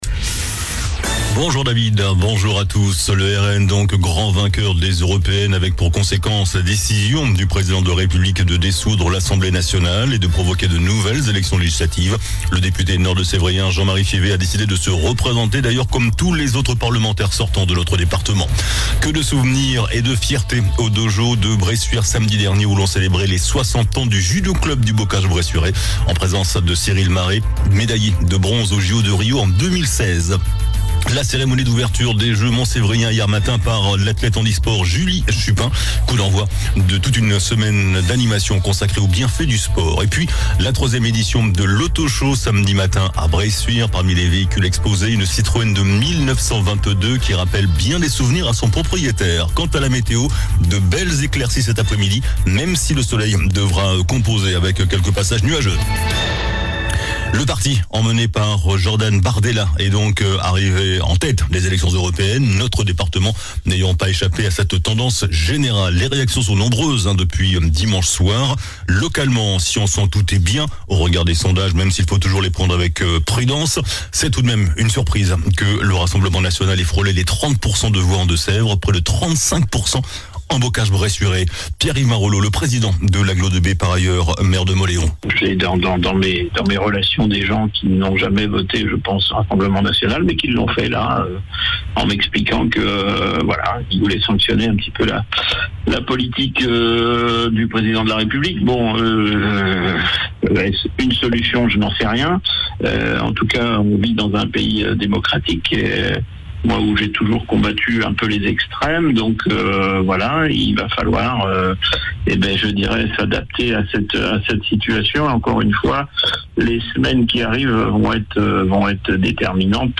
JOURNAL DU MARDI 11 JUIN ( MIDI )